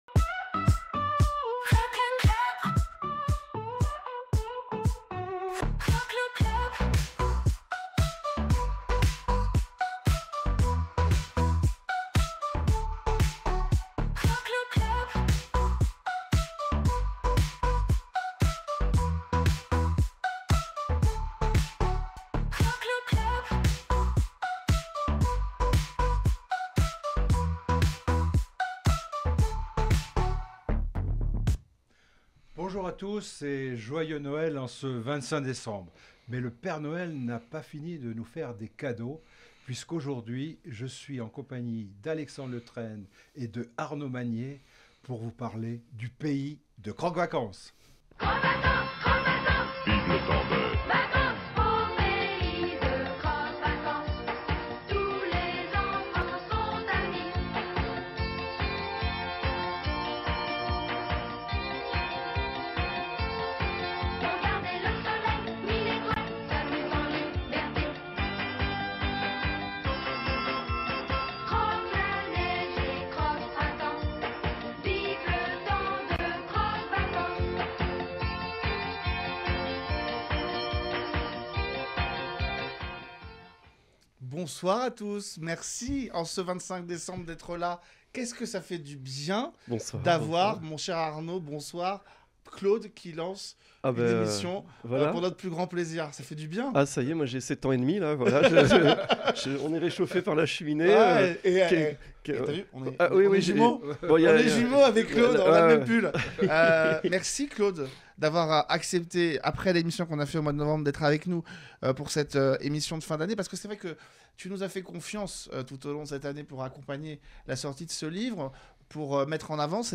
Nous voulions vous réserver un cadeau en ce 25 décembre : une émission spéciale en compagnie de et lancée par Claude Pierrard.